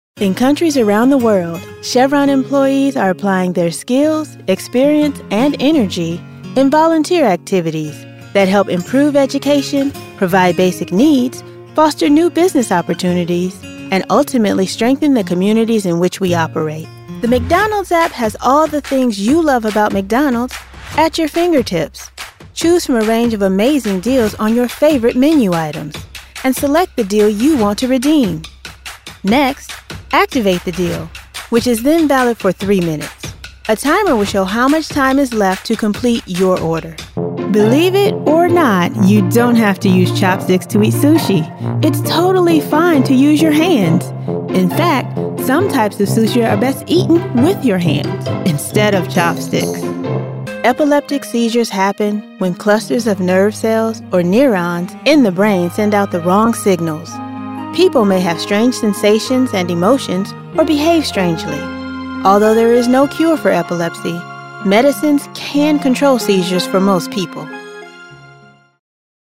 Narration Demo
• 3 Zigma Chi Lollipop Microphone with shock mount
• Fully treated recording space
• Voice: Female Young Adult, Middle Age
• Accent: neutral American English